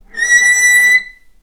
vc-B6-ff.AIF